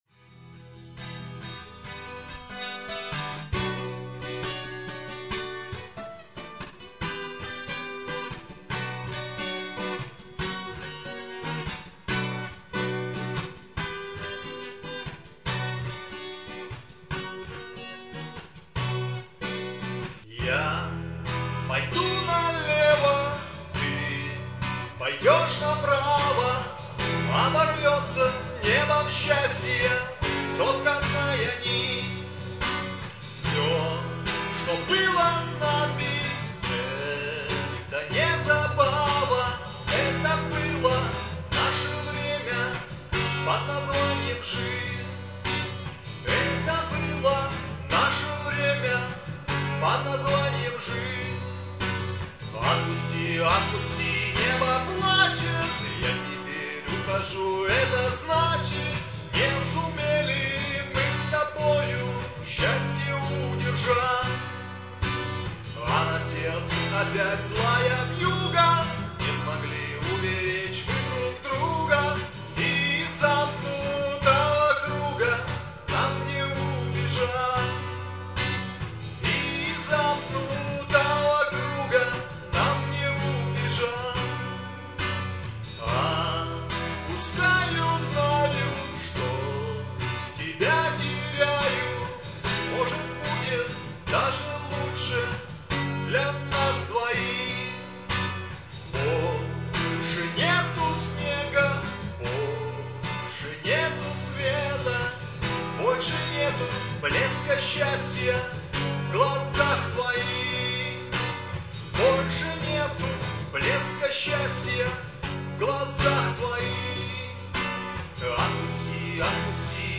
Армейские и дворовые песни под гитару